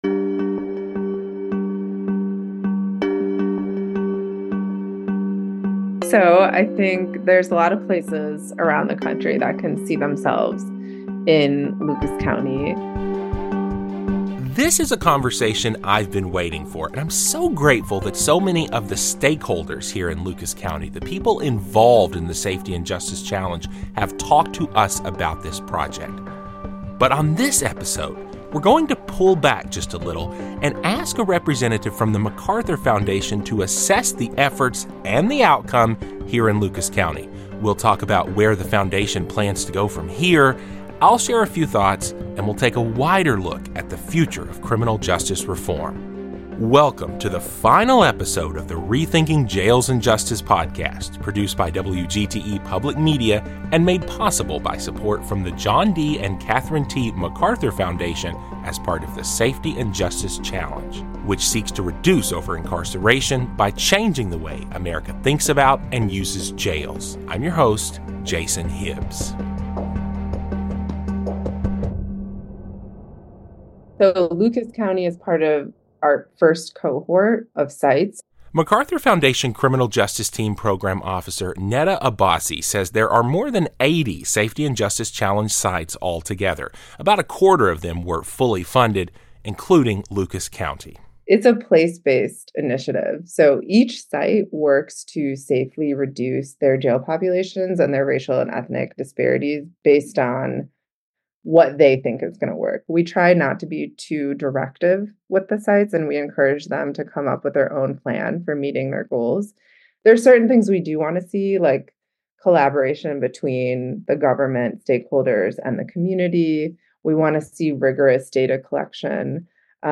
This final episode explores the future of criminal justice reform in Lucas County and beyond. A representative from the MacArthur Foundation evaluates the progress and challenges of the Safety and Justice Challenge. Local leaders share how they’re determined to keep the momentum going despite ongoing struggles and new challenges.